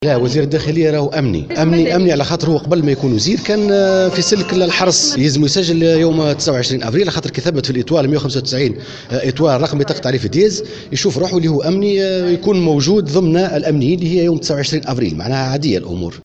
Contacté par Jawhara FM, le président de l'ISIE, Mohamed Tlili Mansri, a expliqué que le ministre de l'intérieur est avant tout un agent sécuritaire et appartenait au corps de la garde nationale avant d'être désigné ministre.